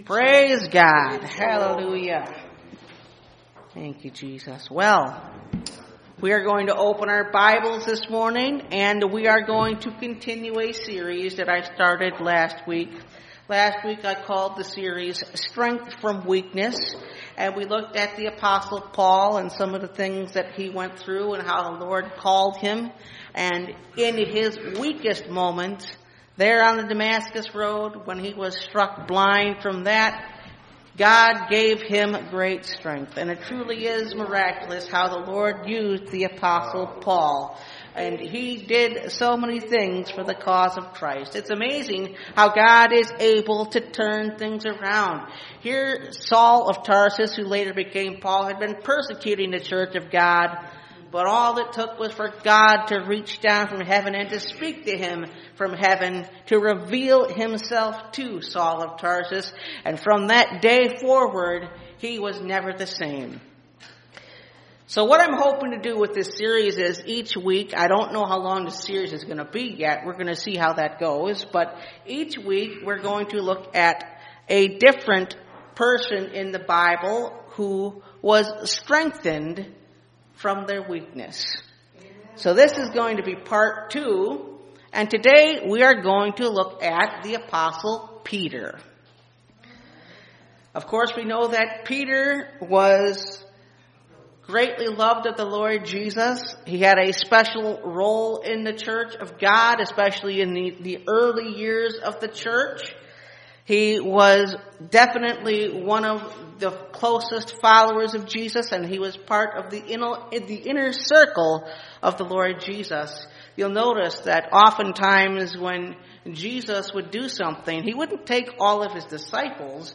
Strength From Weakness – Part 2 (Message Audio) – Last Trumpet Ministries – Truth Tabernacle – Sermon Library
Service Type: Sunday Morning